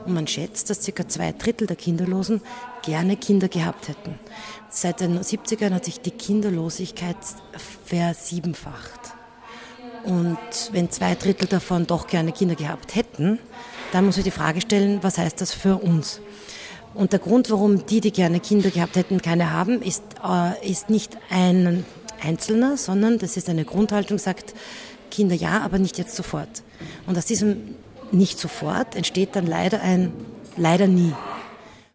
Grußwort